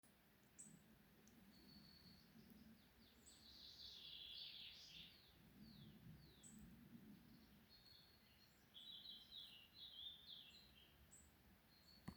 Birds -> Finches ->
Hawfinch, Coccothraustes coccothraustes
StatusPair observed in suitable nesting habitat in breeding season